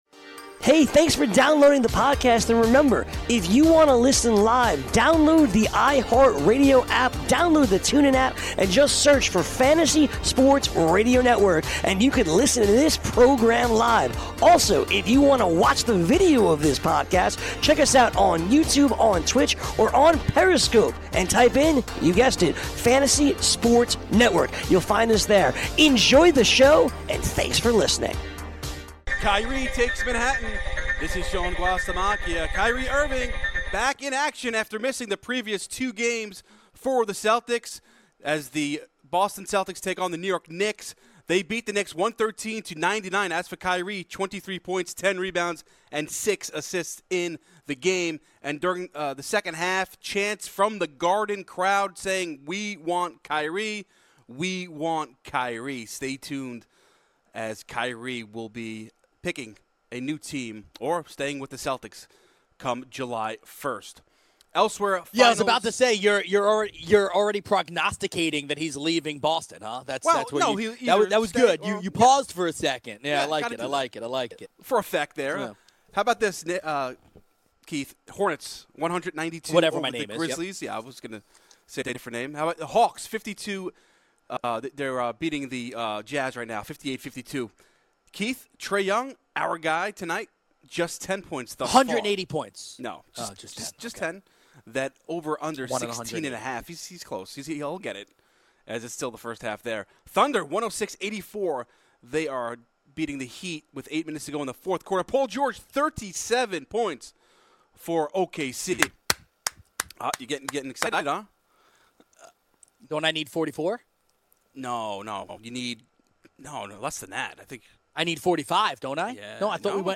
He sounds so unsure of himself.